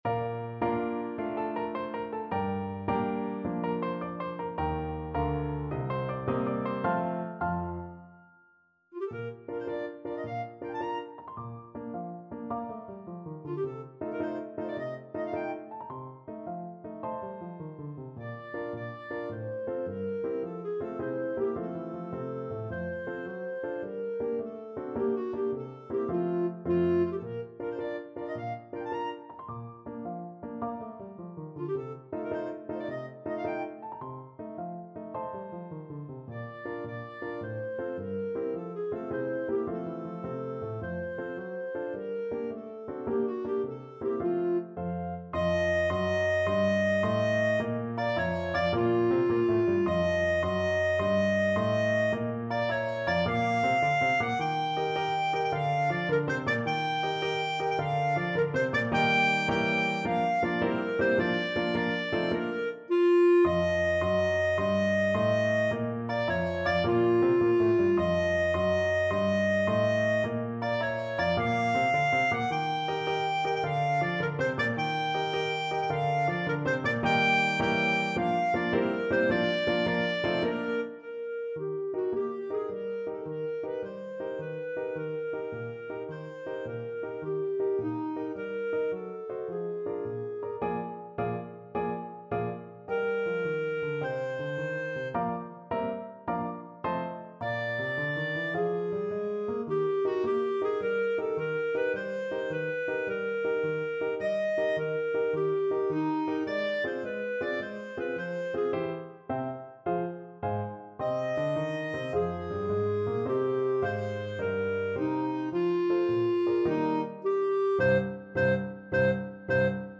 Clarinet
6/8 (View more 6/8 Music)
Eb5-Bb6
. =106 Tempo de Marcia
Arrangement for Clarinet and Piano
Bb major (Sounding Pitch) C major (Clarinet in Bb) (View more Bb major Music for Clarinet )
106 B.P.M.